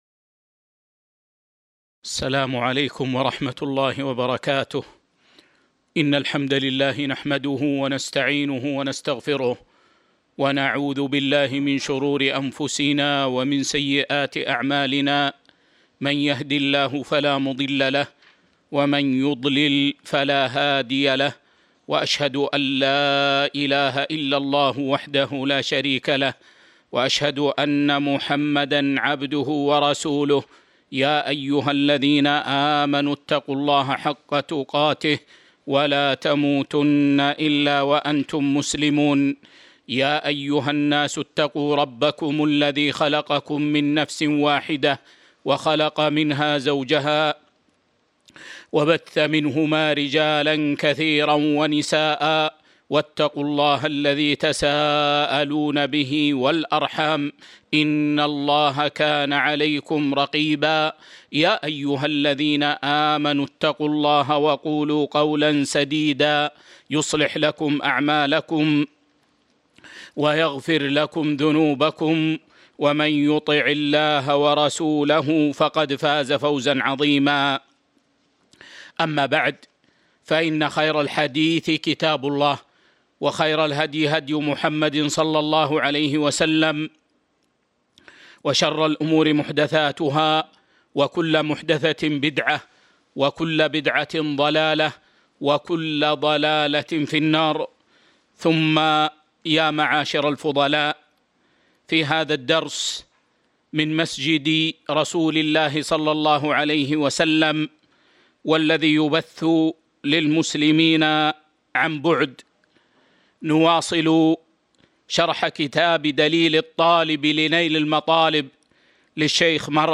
تاريخ النشر ٢٠ ربيع الثاني ١٤٤٢ هـ المكان: المسجد النبوي الشيخ